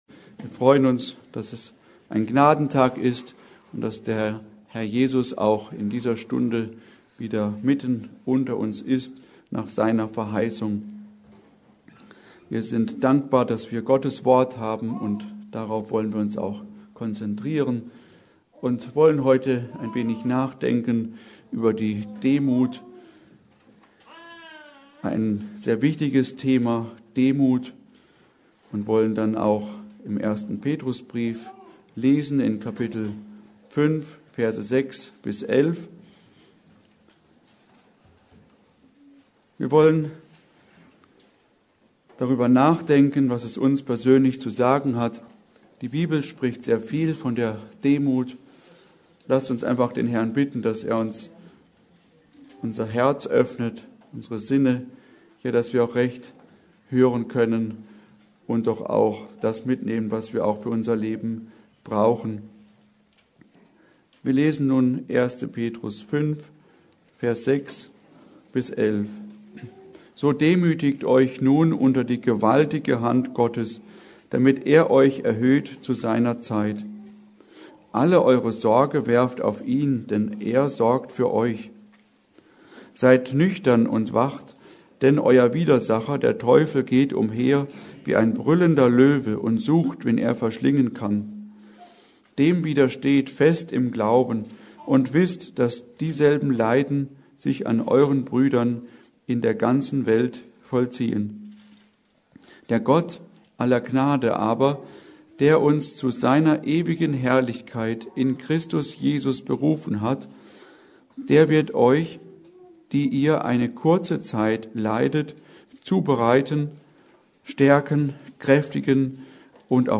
Predigt: Demut